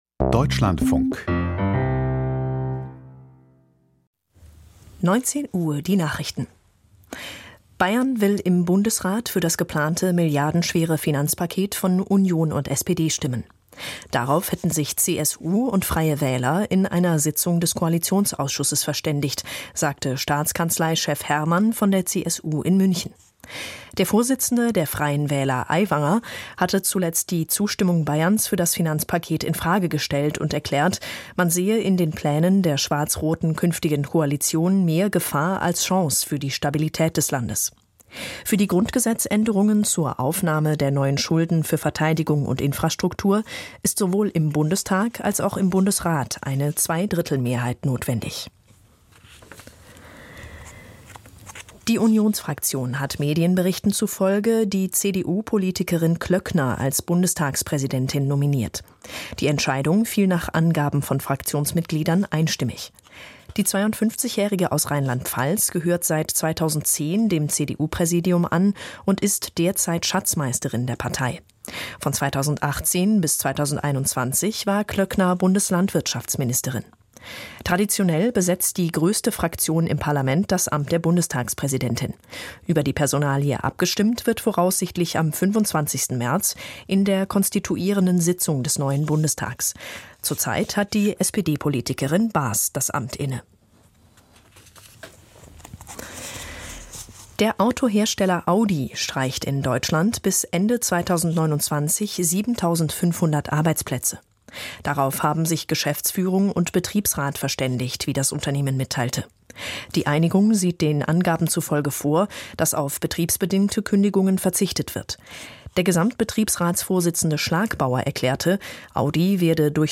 Die Nachrichten
News aus der Deutschlandfunk-Nachrichtenredaktion.